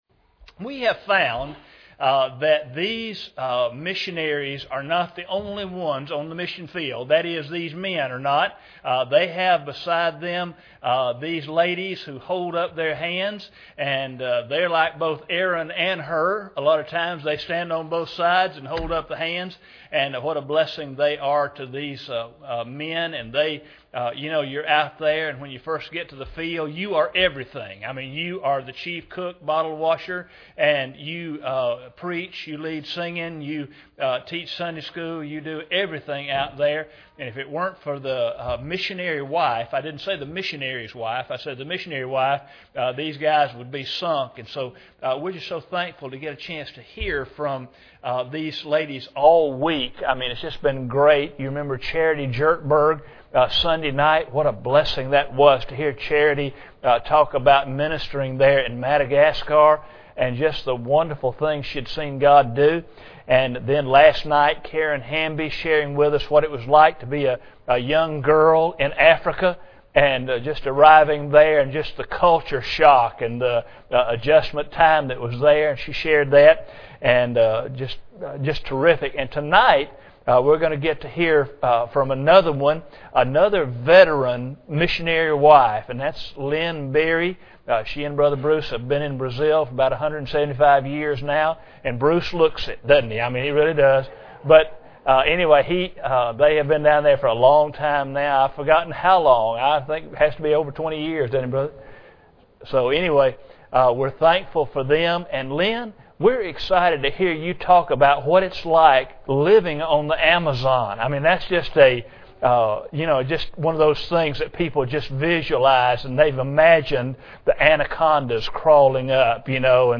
Series: 2012 Missions Conference
Service Type: Special Service